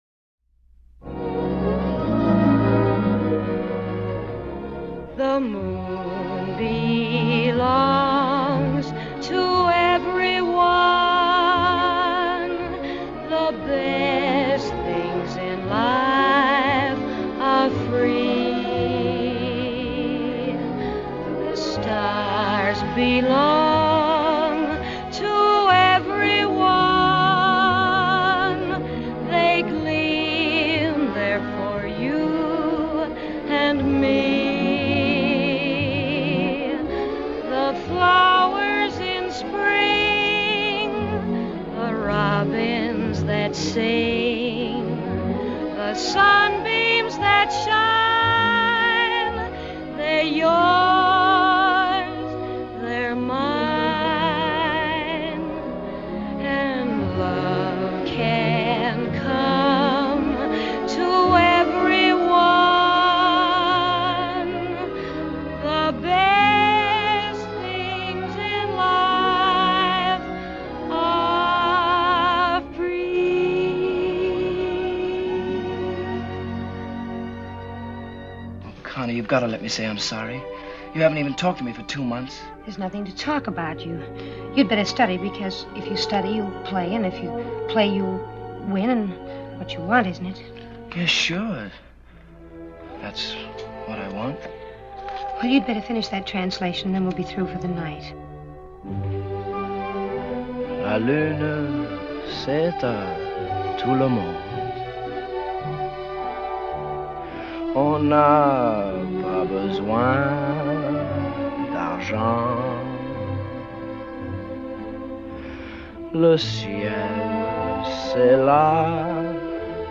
1927   Genre: Musical   Artist